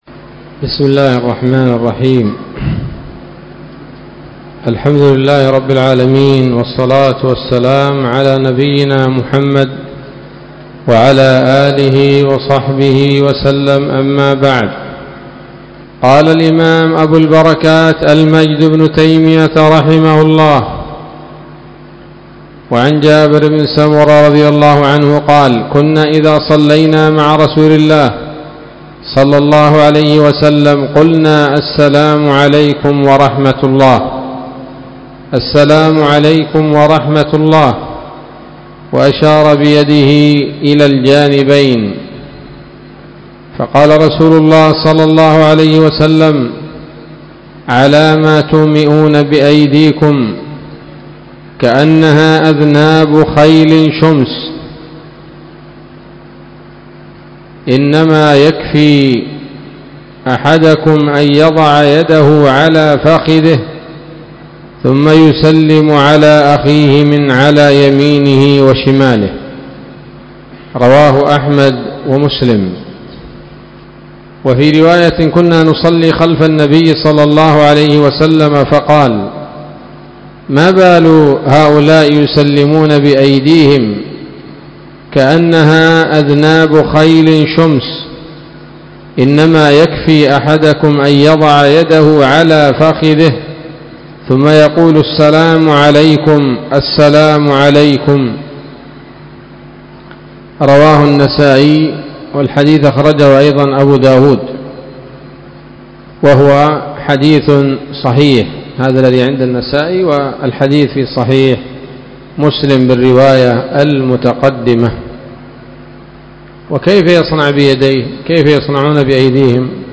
الدرس الثاني والتسعون من أبواب صفة الصلاة من نيل الأوطار